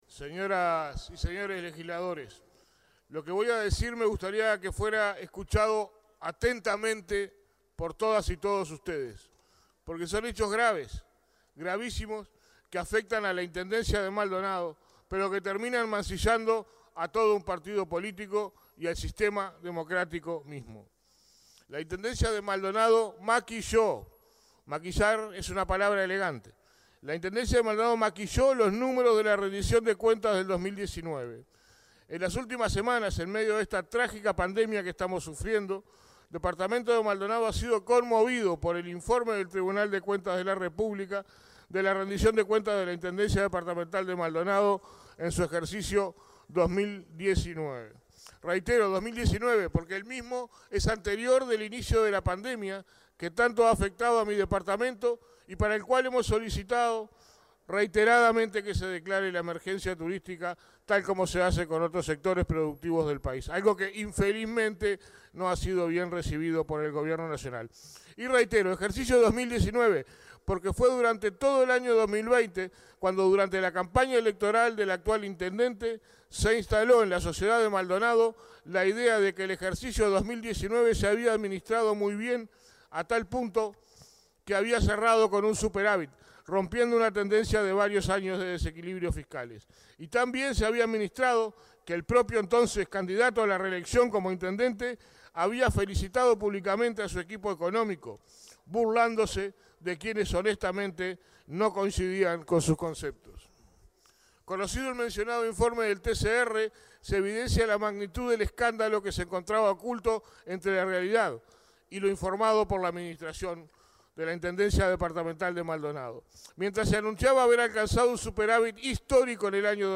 Audio con intervención del diputado Eduardo Antonini en Cámara de Represnetantes disponible para su descarga